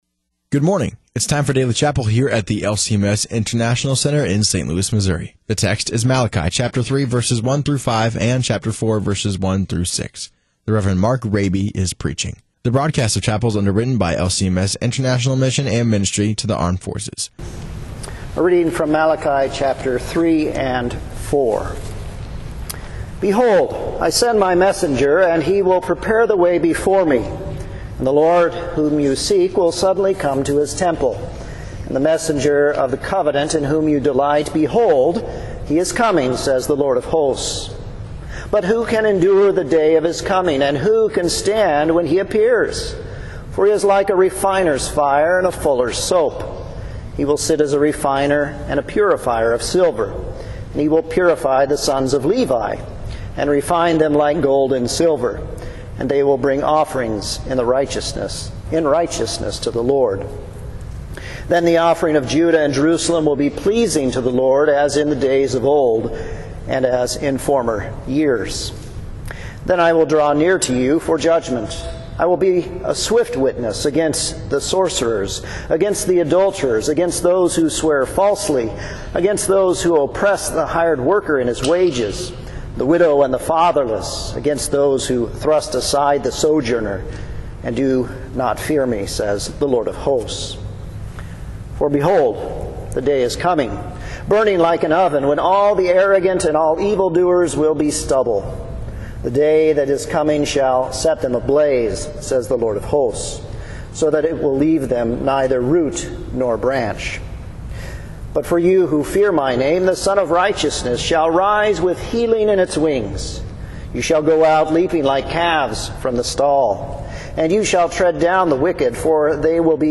>> The broadcast of chapel services is brought to you by LCMS International Mission and Ministry to Armed Forces.